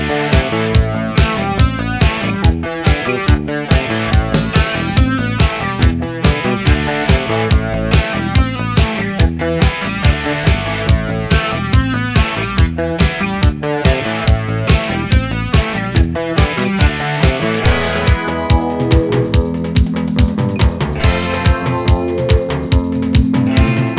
Full Version Pop (1980s) 5:50 Buy £1.50